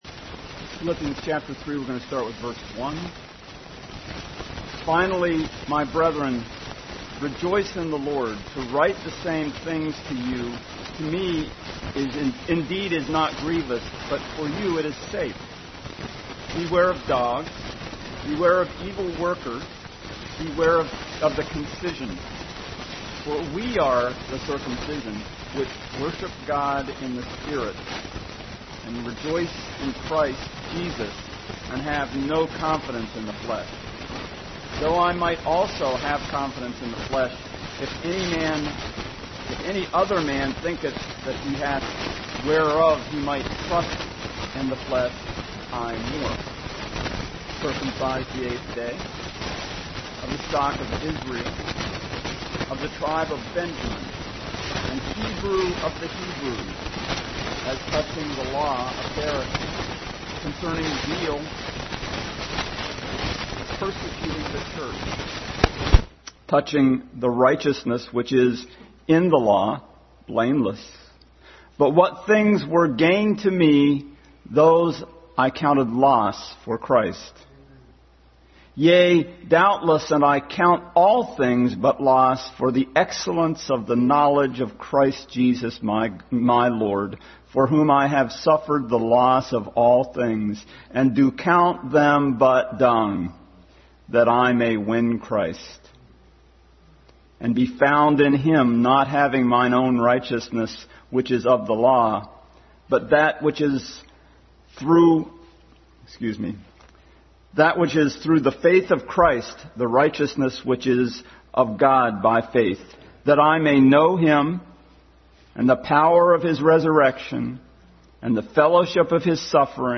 Adult Sunday School continued study in Philippians.
Revelation 22:15 Service Type: Sunday School Adult Sunday School continued study in Philippians.